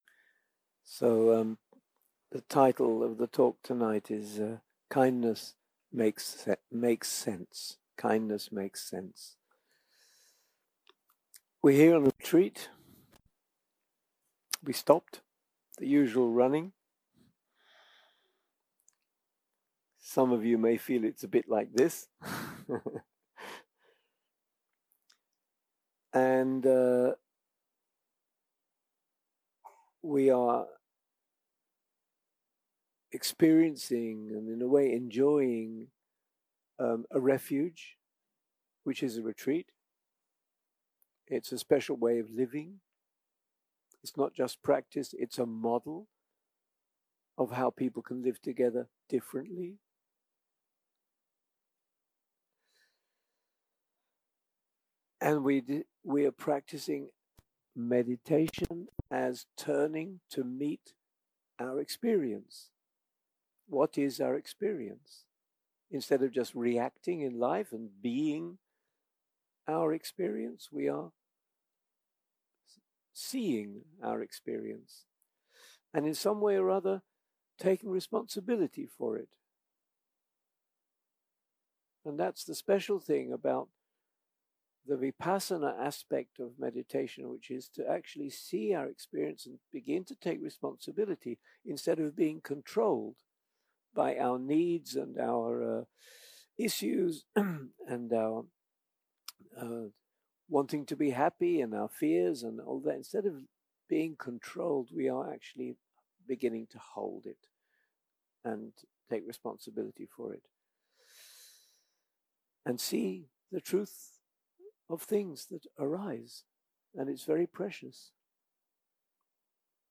Dharma Talks שפת ההקלטה